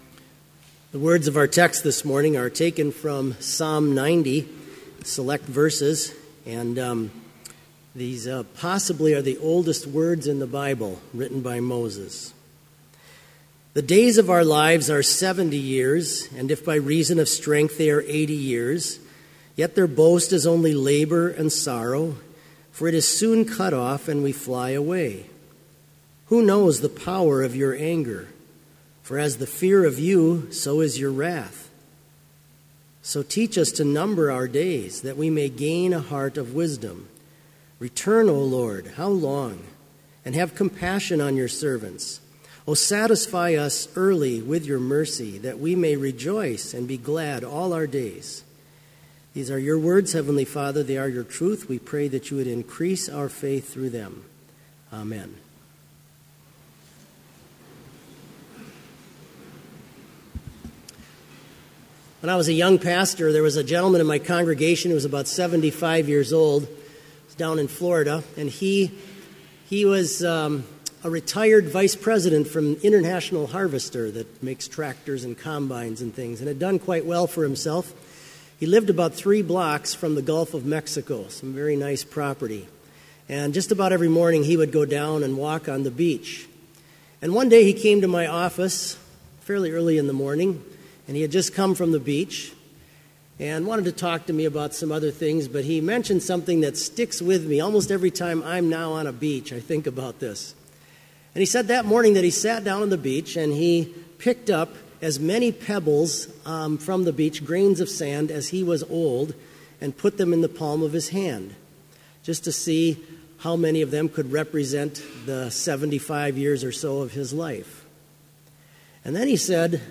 Complete service audio for Chapel - September 16, 2016